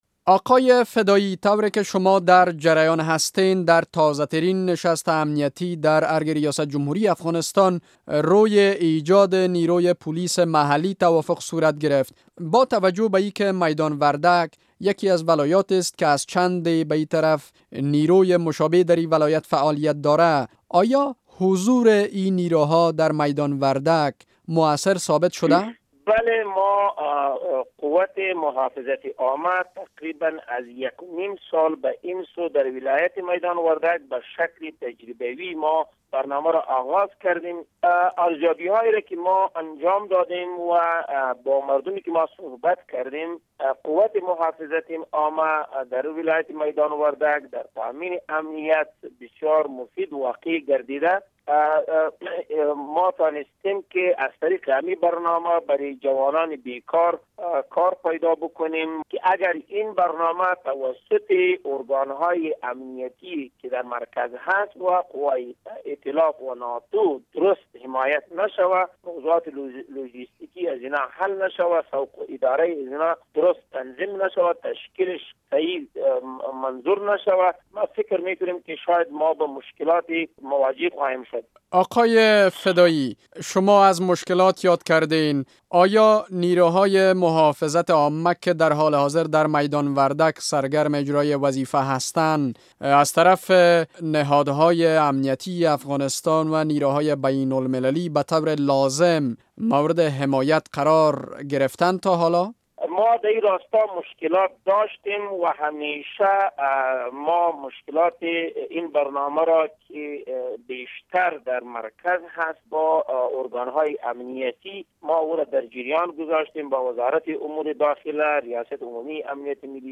مصاحبه با حلیم فدایی در مورد موثریت قوت محافظت عامهء ولایت میدان وردک